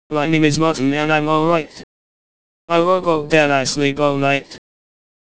Custom Voice Creation
Click for demo wav file generated from just 42 seconds of audio, with raw (error-riddled) transcribed text to match.